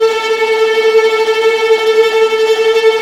Index of /90_sSampleCDs/Roland L-CD702/VOL-1/STR_Vlas Bow FX/STR_Vas Tremolo